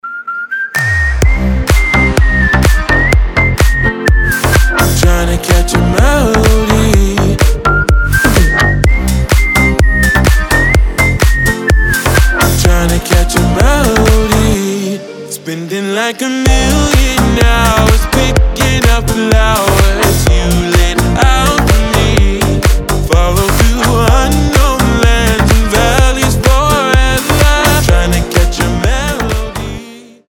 свист
мужской голос
легкие
теплые
slap house